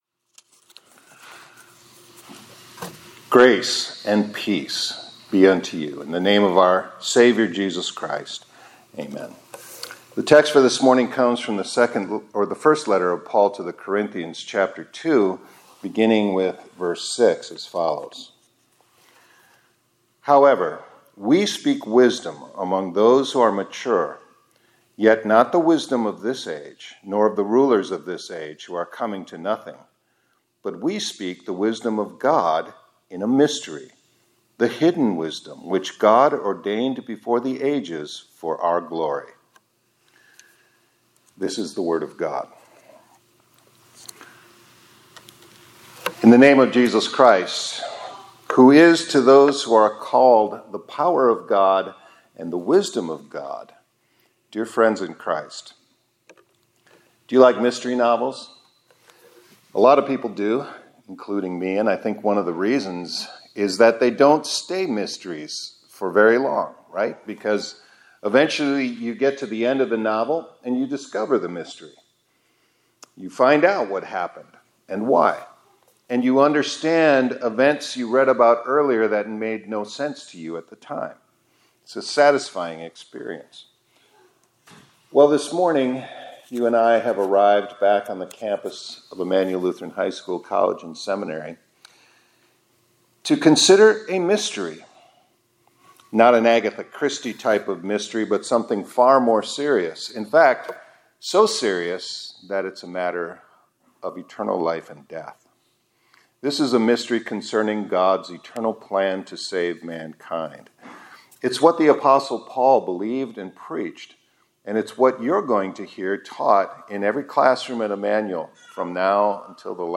2025-08-25 ILC Chapel — The Mystery of God’s Wisdom